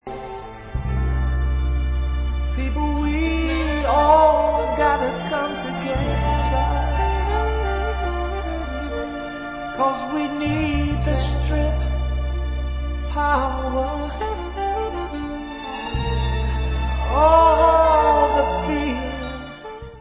sledovat novinky v oddělení Dance/Soul